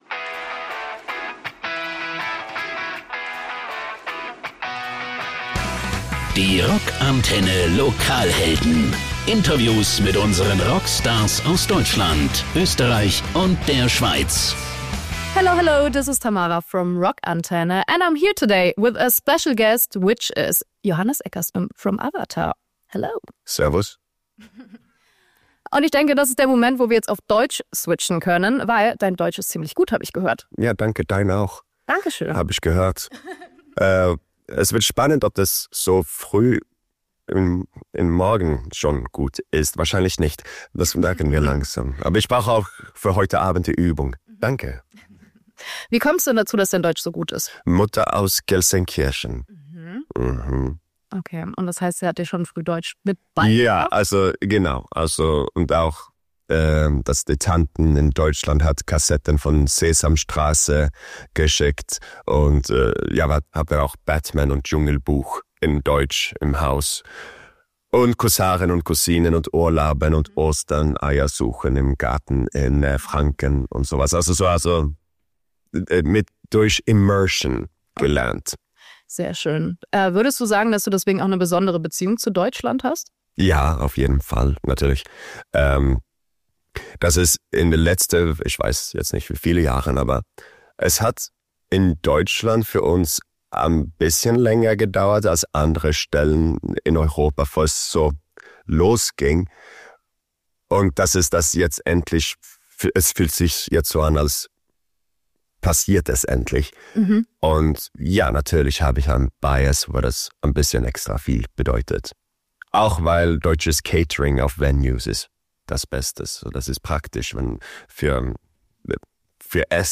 Heute haben wir einen ganz besonderen Gast am Mikrofon: Johannes Eckerström, den visionären Frontmann von Avatar.